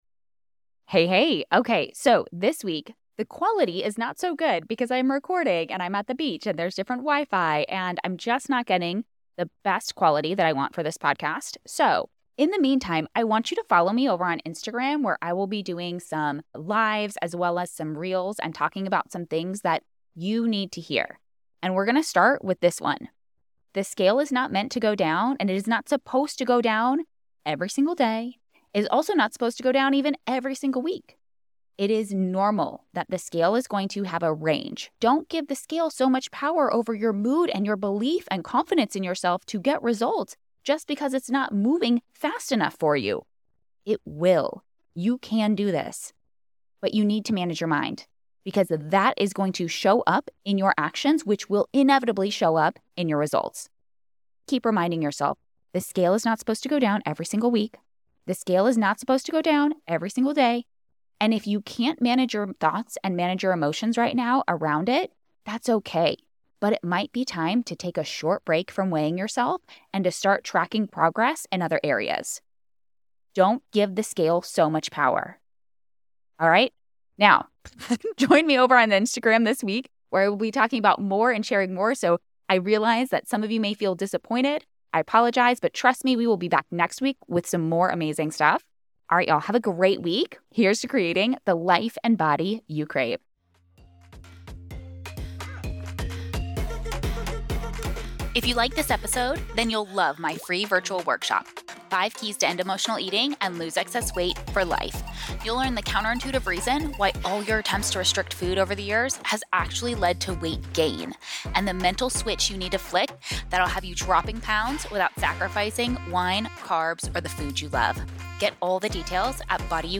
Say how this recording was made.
The internet did not want to cooperate with me this week, so you're getting a short and sweet message from the beach. Bonus-Message-from-the-beach-.mp3